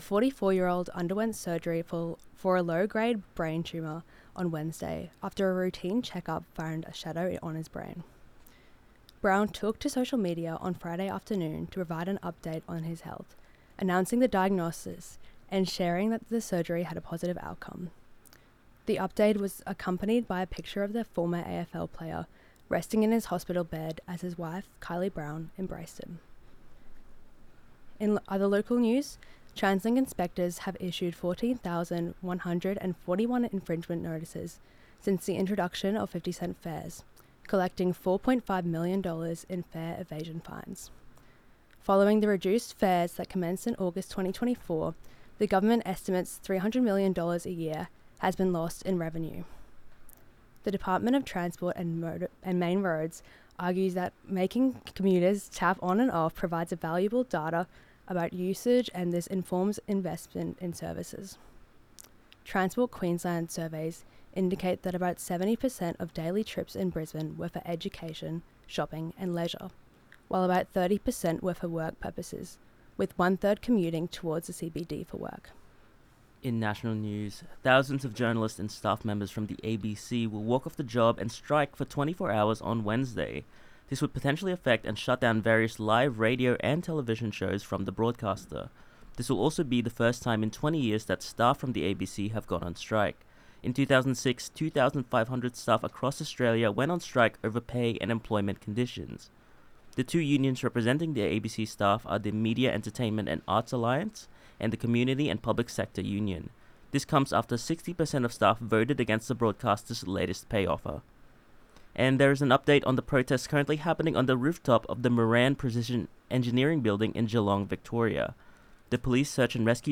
From Wikimedia Commons under CC4.0 Zedlines Bulletin MondayZedlines11am23March.mp3